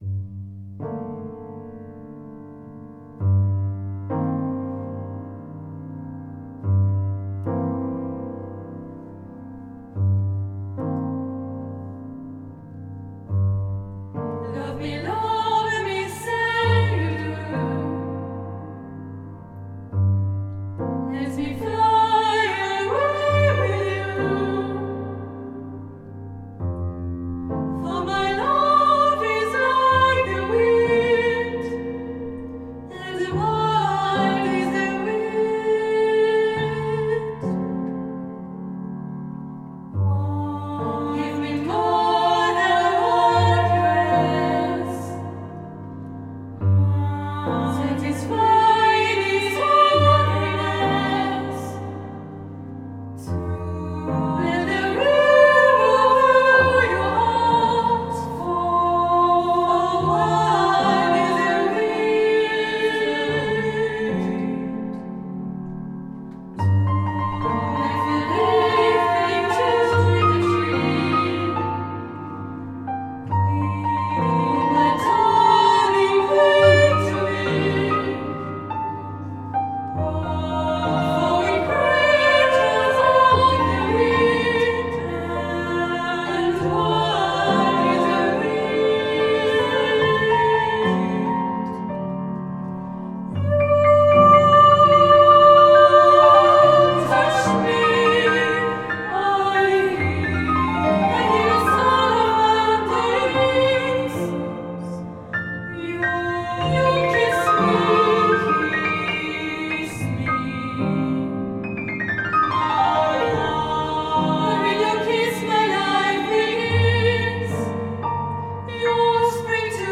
Enregistrement audio piano voix